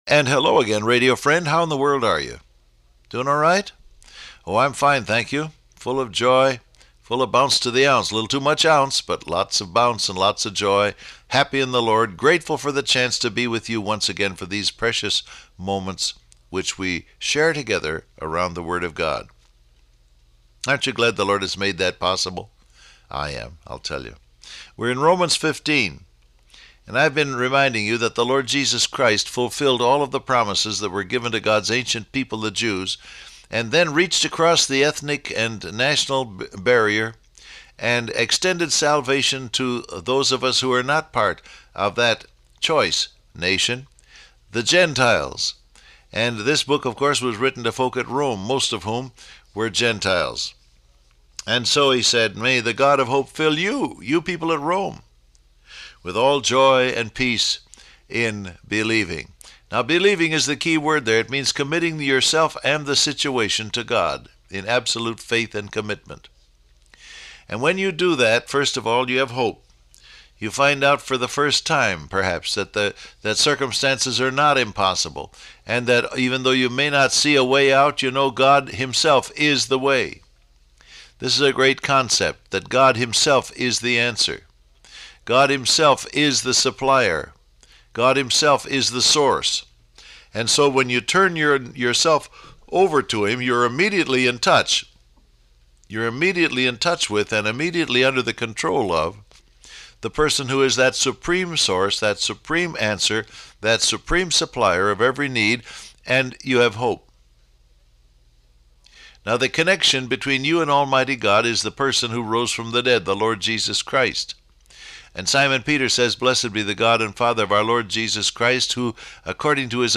Download Audio Print Broadcast #7482 Scripture: Romans 15:8-13 , Philippians 4:6-7 Topics: Believing , God Of Hope , Joy And Peace , Gods Peace Transcript Facebook Twitter WhatsApp And hello again, radio friend.